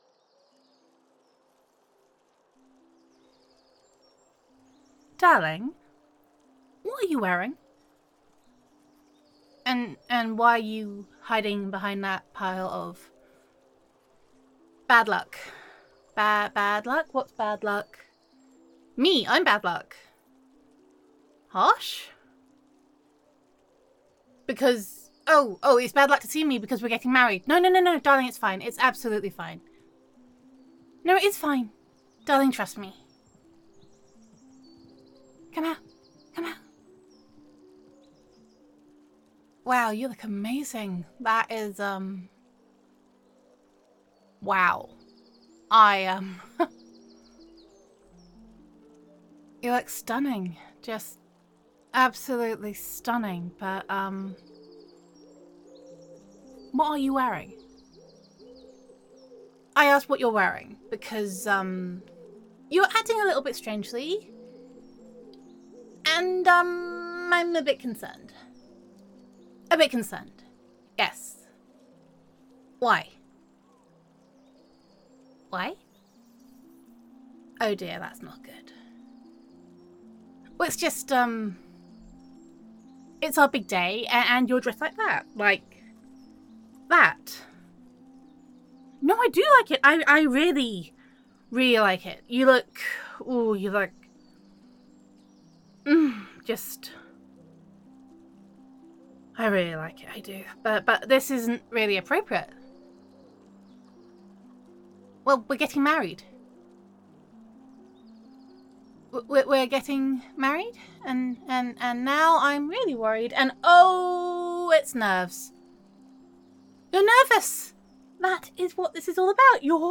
There is a certain amount of microphone crackle that I just cannot save. Tis the problem of breaking in a new microphone.
[F4A] Levelling Up [Wedding Day][Fiancée Roleplay][Boss Battle][Laser Toasters][Gender Neutral][Your Wedding Day Is Not Going Quite How You Expected It To]